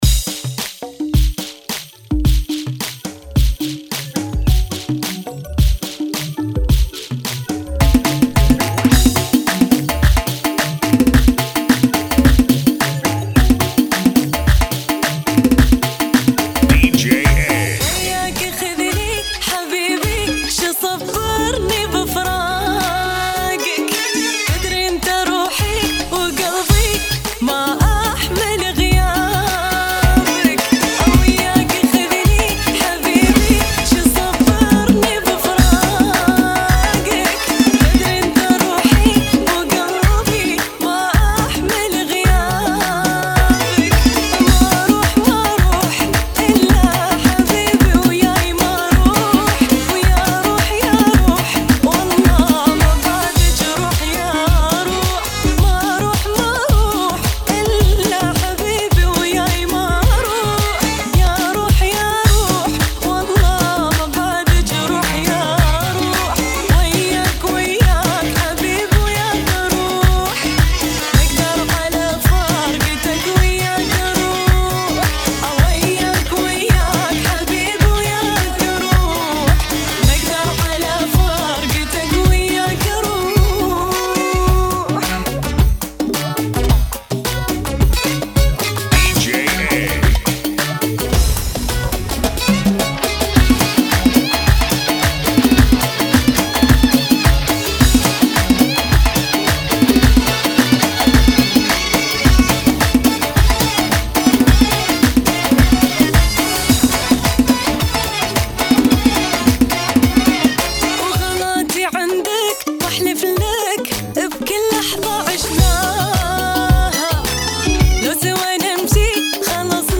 108 Bpm